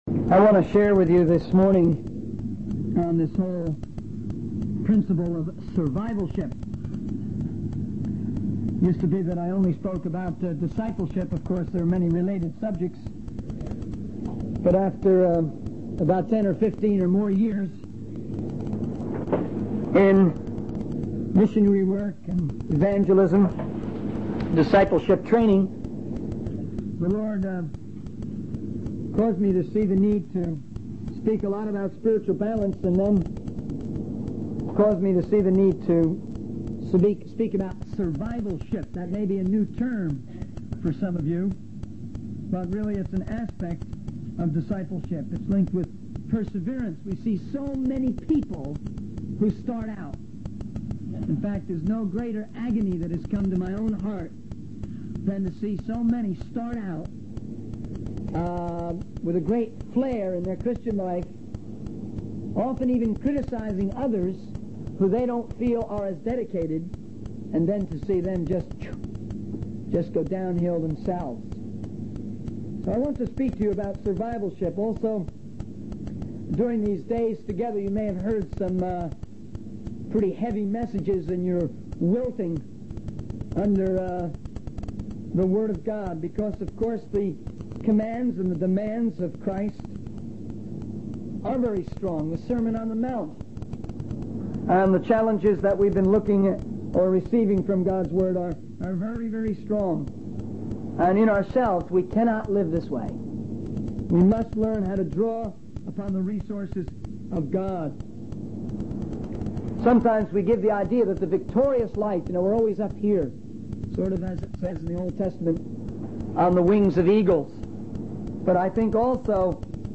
In this sermon, the speaker emphasizes the importance of accountability in the ministry. He warns that laziness can easily creep in when ministers are not accountable to anyone on a day-to-day basis. The speaker also highlights the need to learn how to handle hurt and persecution, drawing from the Sermon on the Mount.